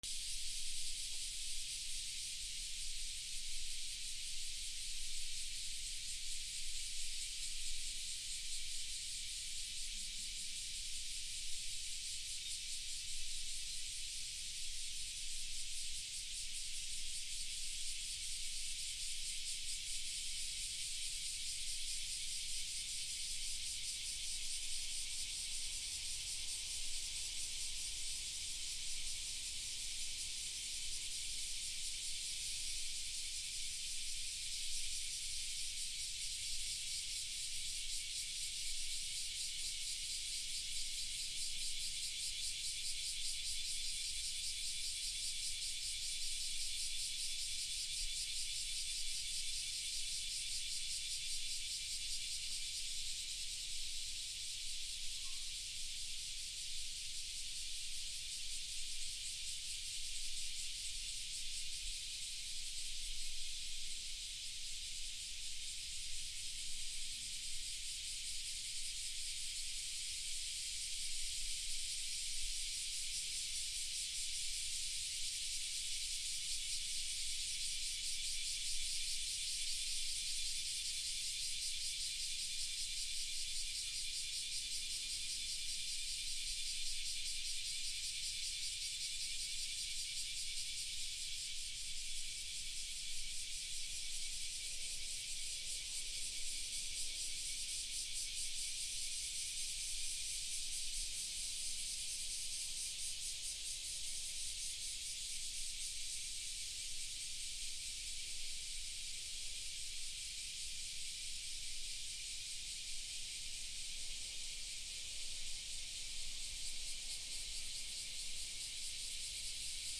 セミ 神社 オフ気味
/ B｜環境音(自然) / B-25 ｜セミの鳴き声 / セミの鳴き声_20_クマゼミ